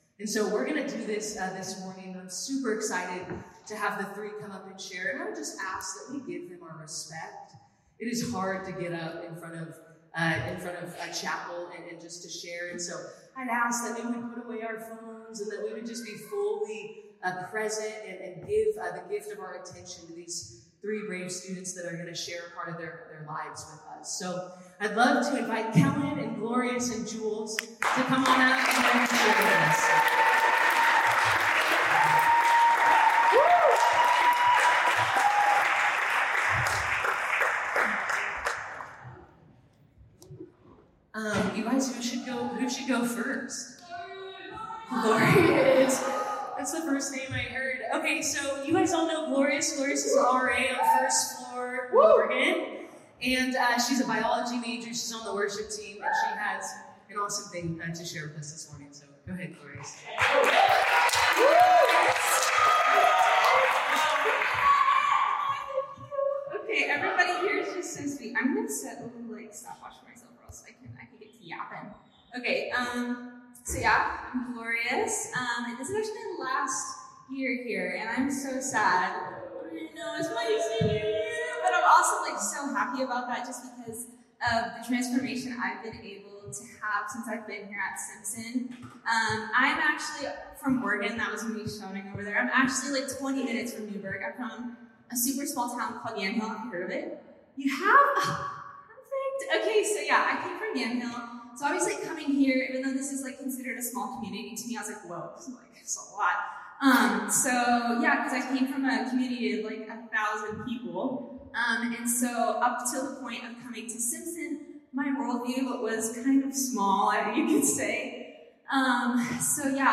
This talk was given in chapel on Friday, February 28th, 2025 God Bless you.